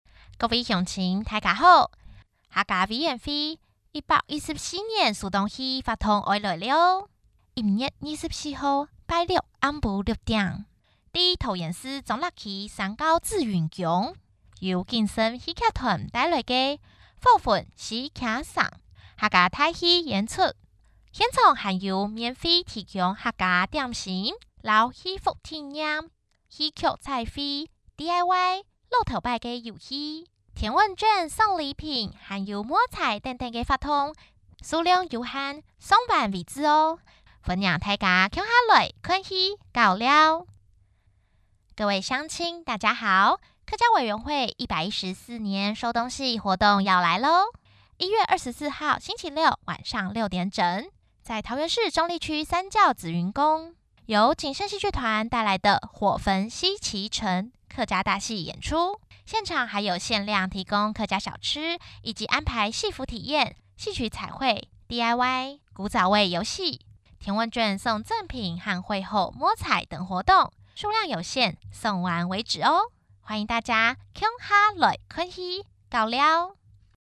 女性配音員 國語配音 客語配音員